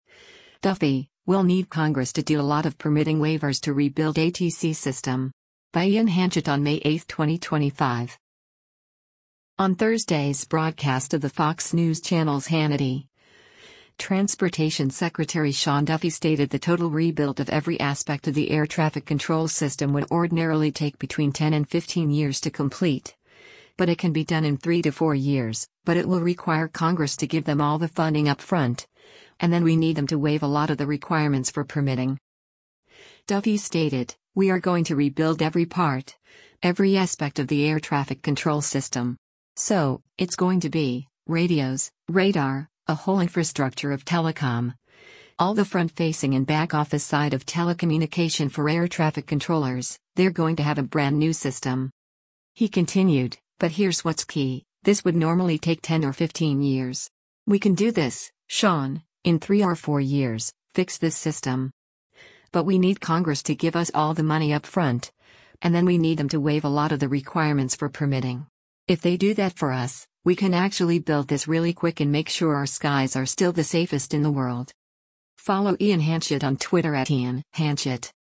On Thursday’s broadcast of the Fox News Channel’s “Hannity,” Transportation Secretary Sean Duffy stated the total rebuild of every aspect of the air traffic control system would ordinarily take between ten and fifteen years to complete, but it can be done in three to four years, but it will require Congress to give them all the funding up front, “and then we need them to waive a lot of the requirements for permitting.”